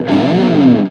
electricguitarhit.ogg